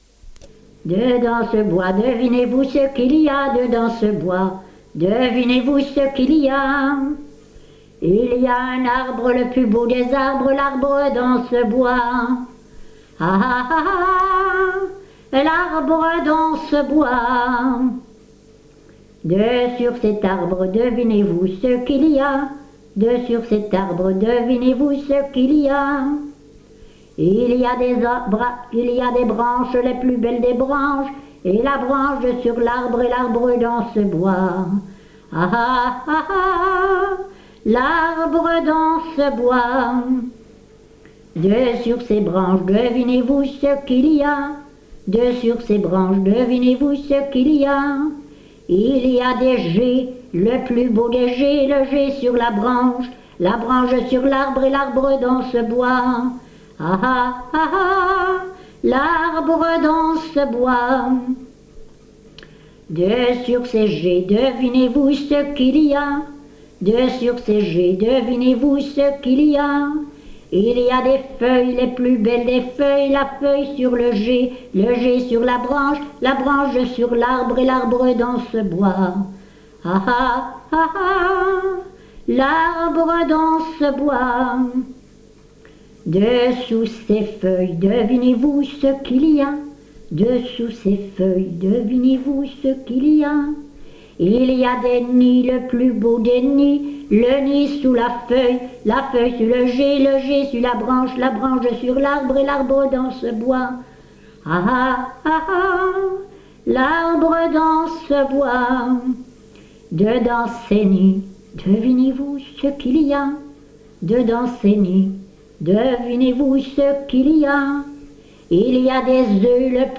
Genre : chant
Type : chanson d'enfants
Lieu d'enregistrement : Lessines
Support : bande magnétique